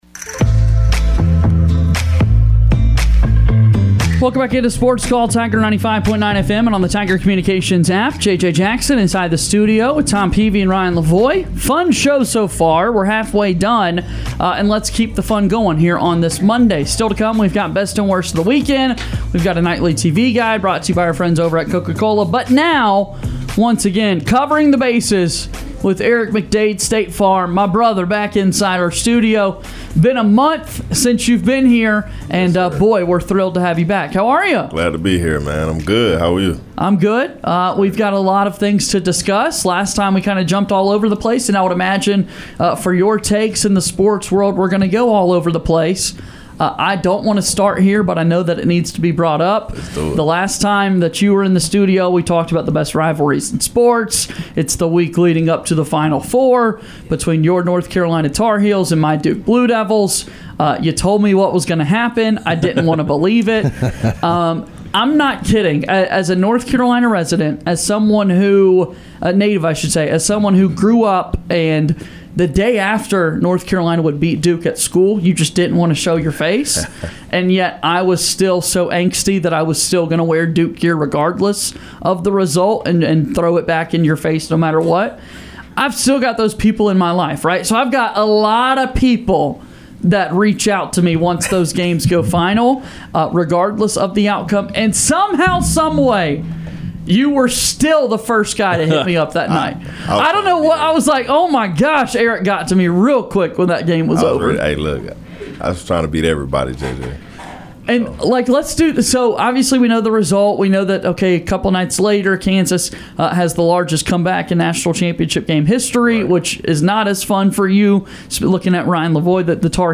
into our SportsCall + Tiger 95.9 studio